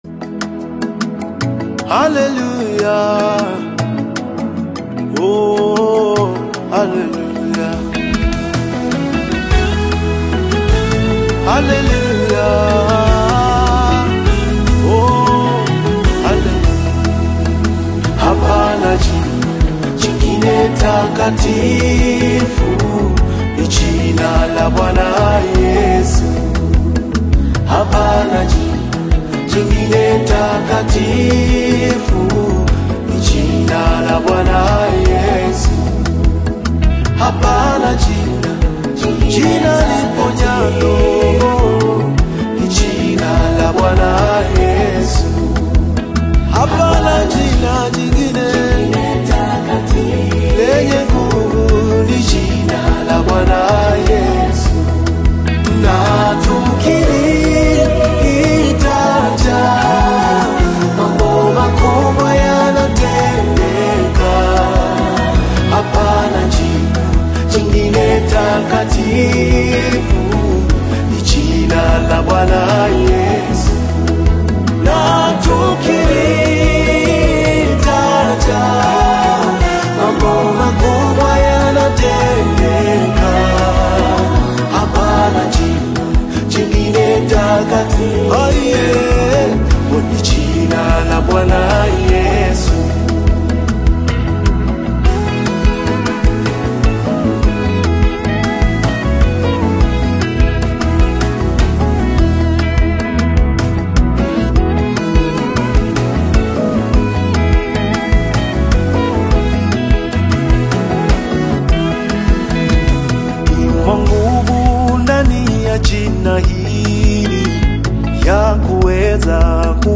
AudioGospel